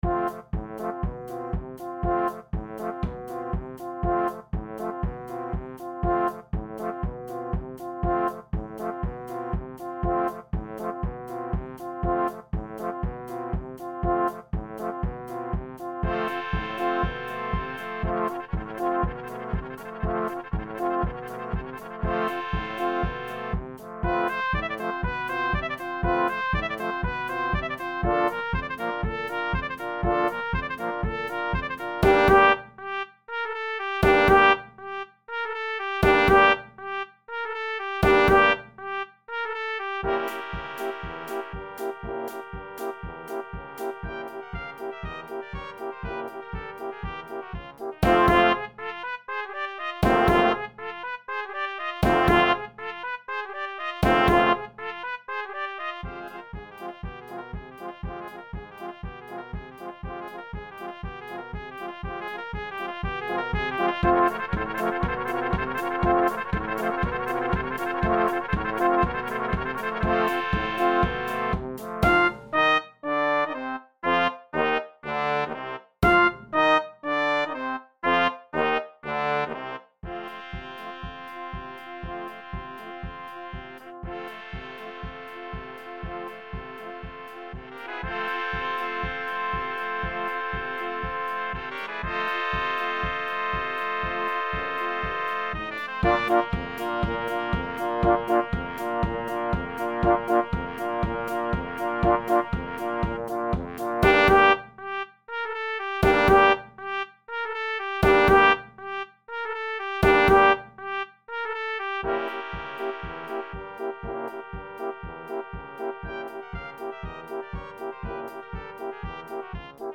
is a traditional Romanian gypsy tune